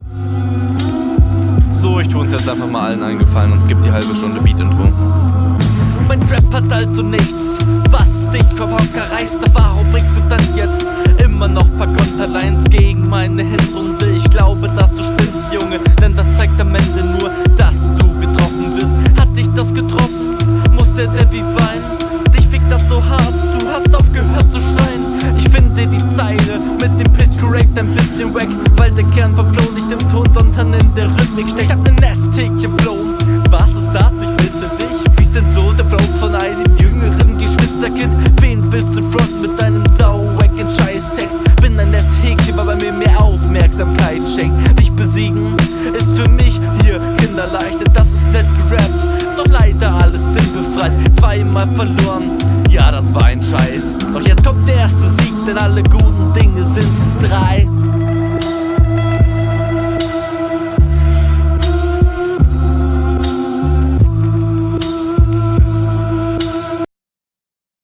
flow geht nicht auf, konstant nicht ganz im takt, versmaß scheitert auch öfter, z.b. in …